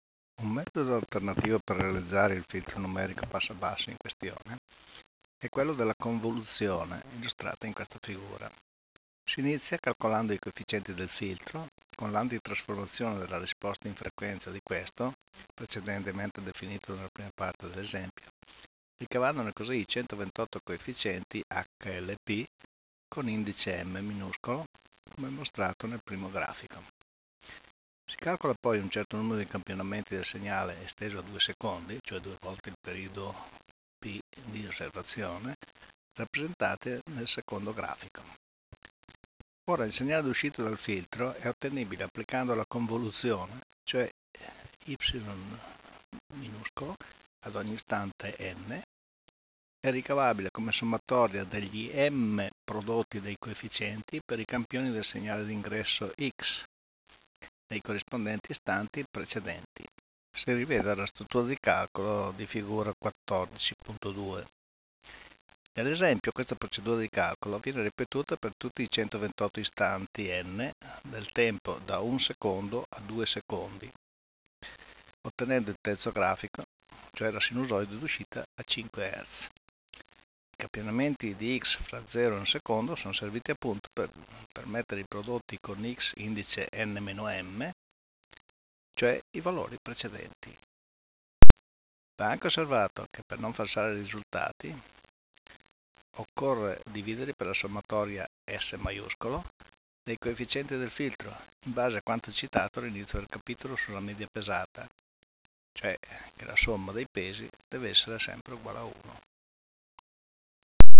[commento audio]